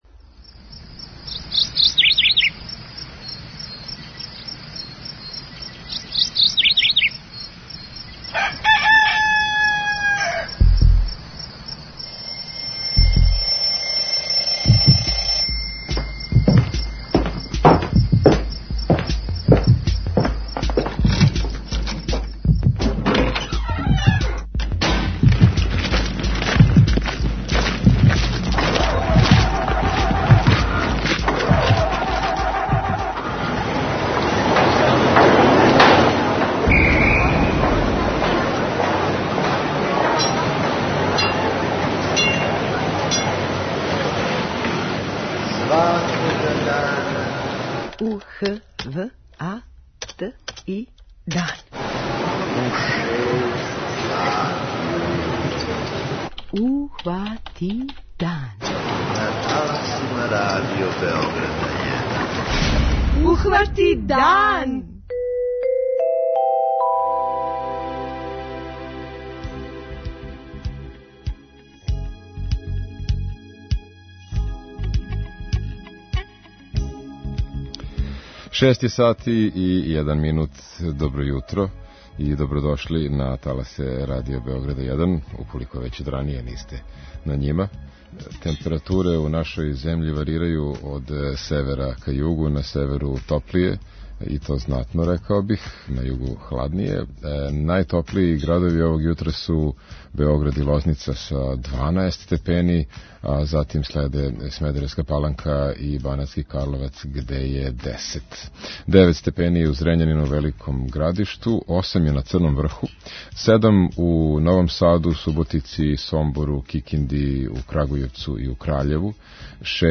преузми : 32.37 MB Ухвати дан Autor: Група аутора Јутарњи програм Радио Београда 1!